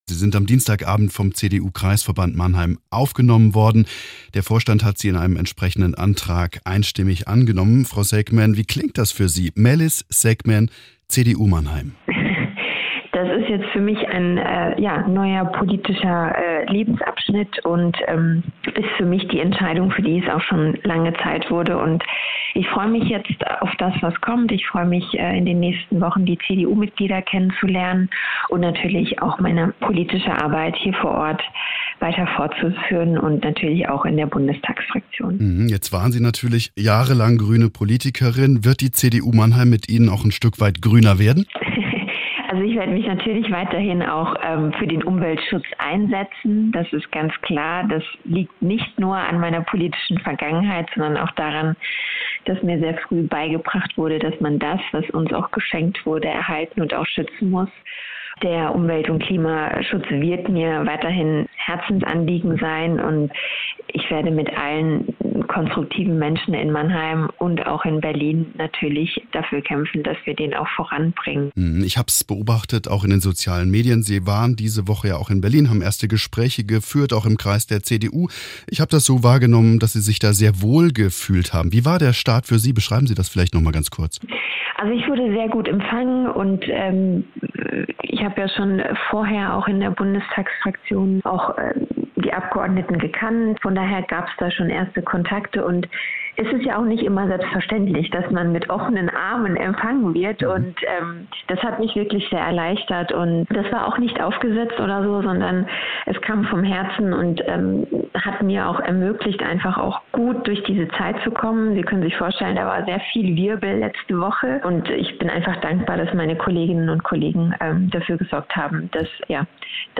Interview mit Melis Sekmen: "Man muss irgendwann die Reißleine ziehen"